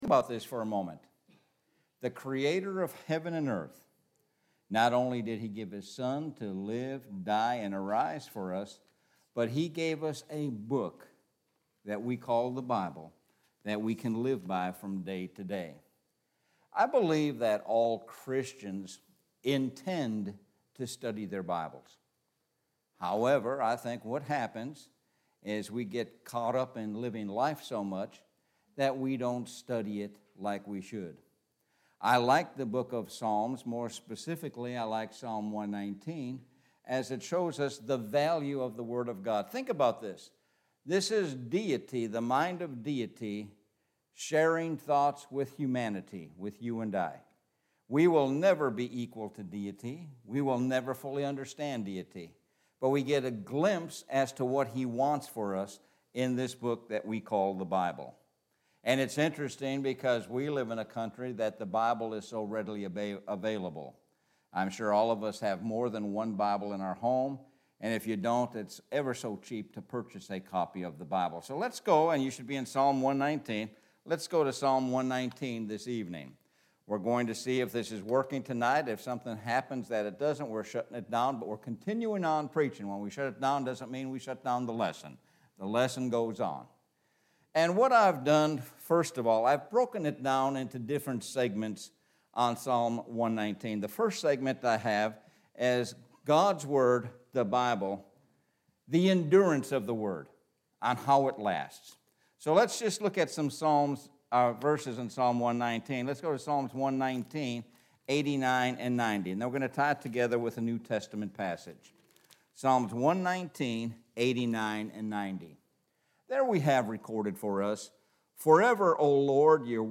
Sun PM Sermon – Psalm 119